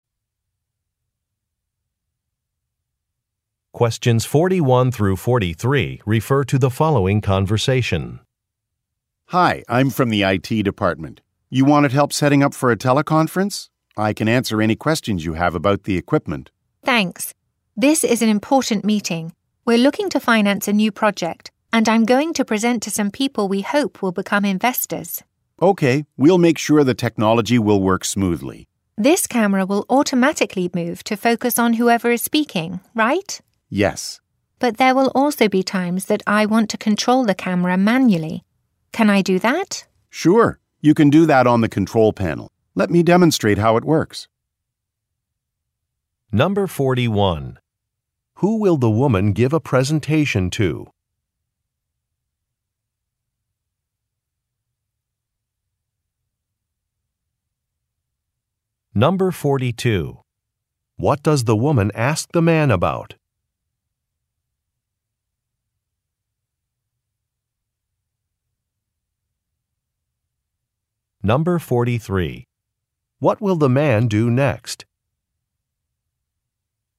Question 41 - 43 refer to following conversation: